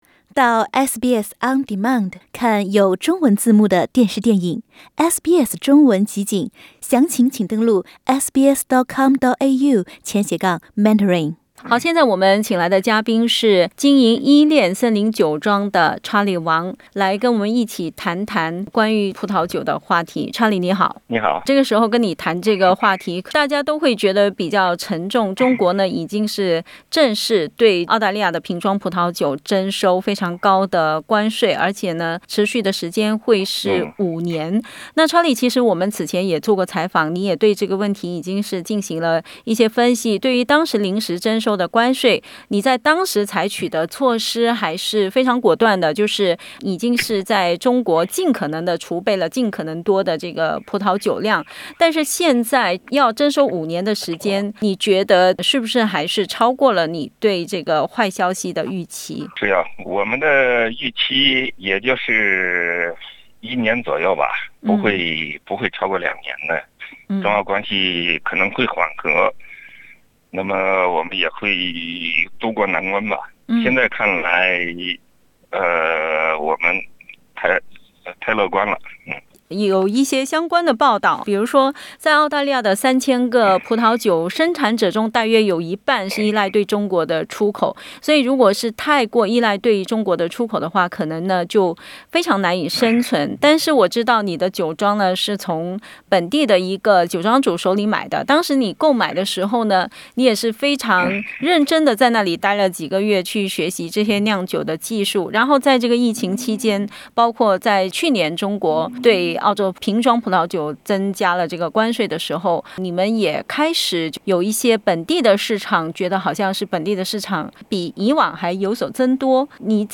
（請聽寀訪，本節目為嘉賓觀點，不代表本台立場） 澳大利亞人必鬚與他人保持至少1.5米的社交距離，請查看您所在州或領地的最新社交限制措施。